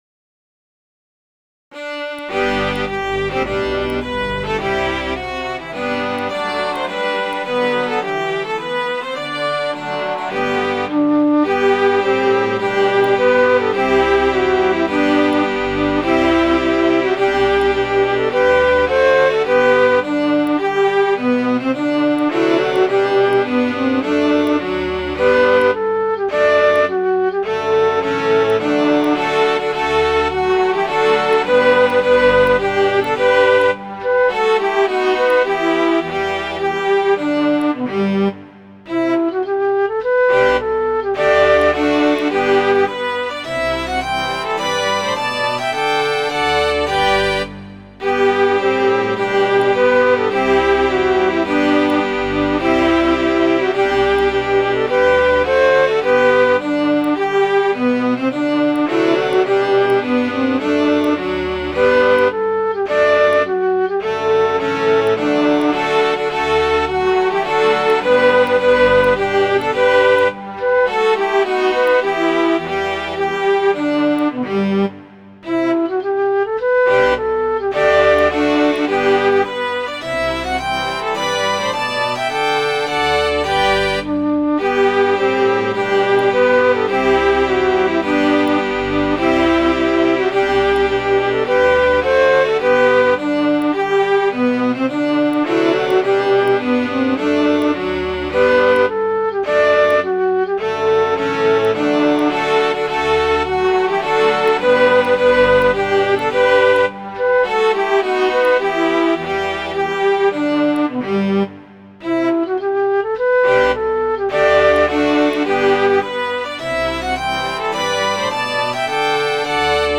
Midi File, Lyrics and Information to Heart of Oak